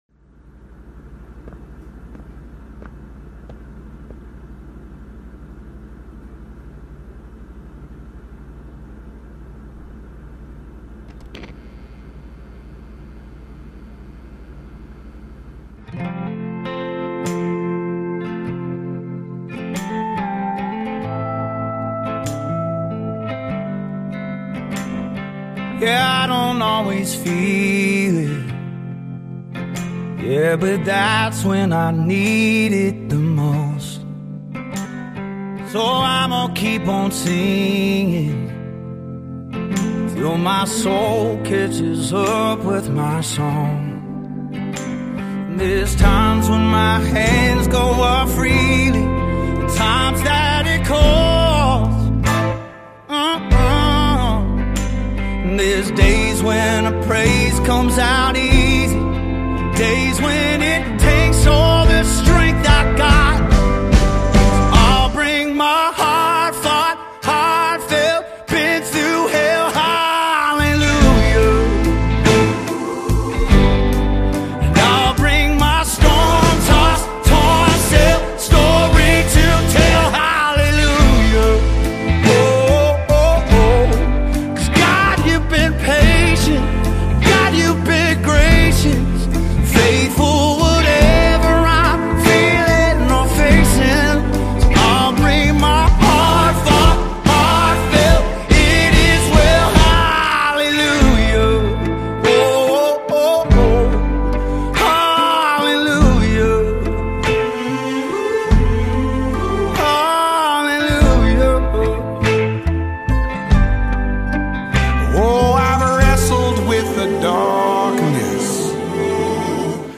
passionate vocal delivery
raw and soulful tone adds depth and authenticity
American Gospel Songs